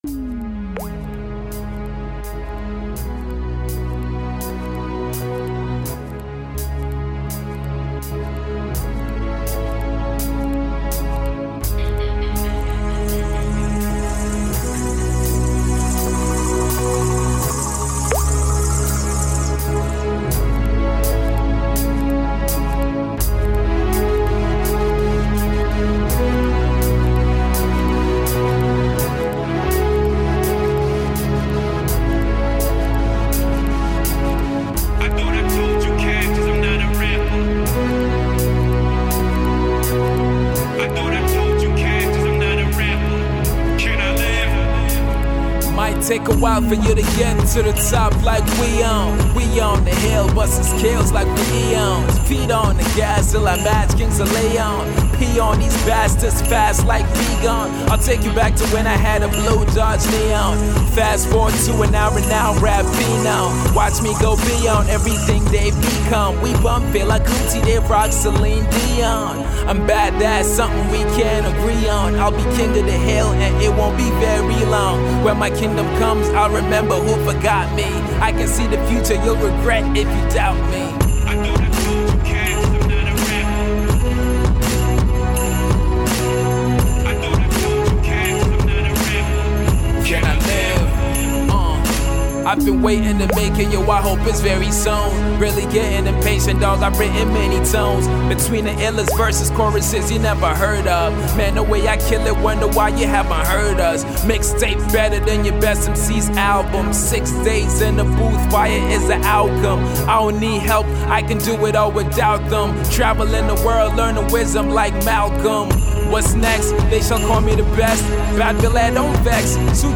all the while staying true to its core hip-hop roots.